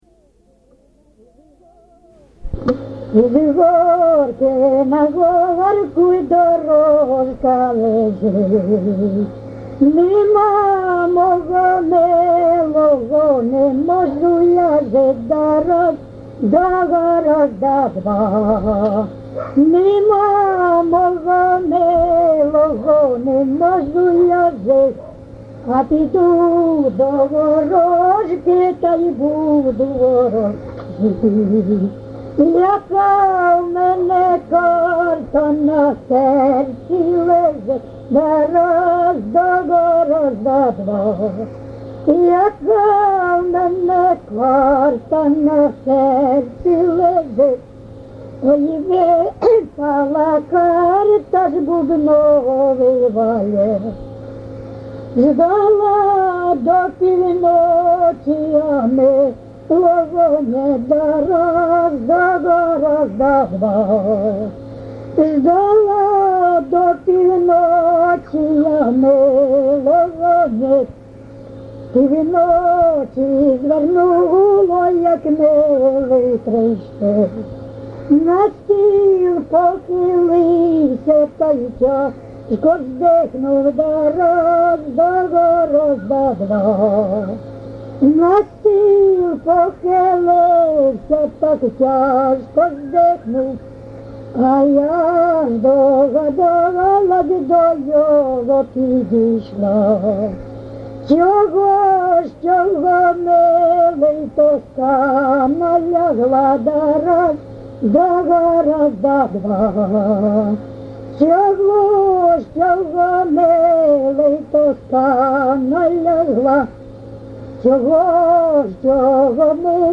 ЖанрПісні з особистого та родинного життя, Солдатські
Місце записус. Коржі, Роменський район, Сумська обл., Україна, Слобожанщина